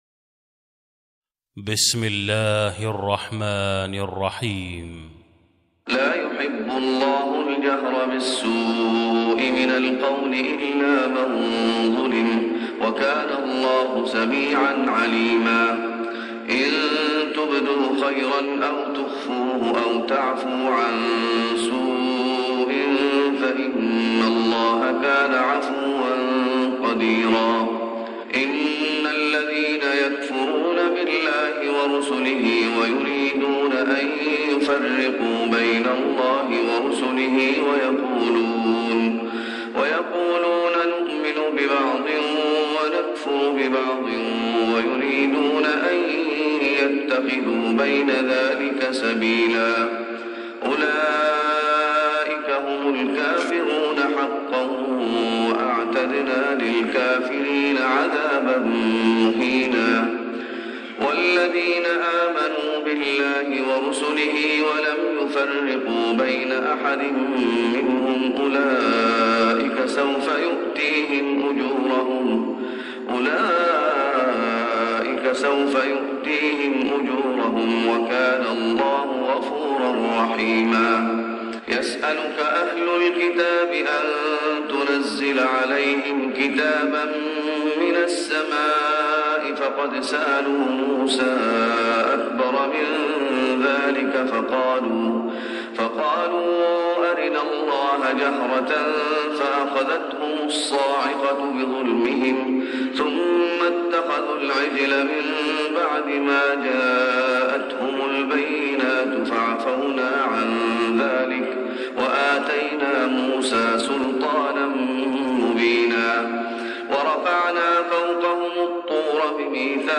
تهجد رمضان 1416هـ من سورة النساء (148-176) Tahajjud Ramadan 1416H from Surah An-Nisaa > تراويح الشيخ محمد أيوب بالنبوي 1416 🕌 > التراويح - تلاوات الحرمين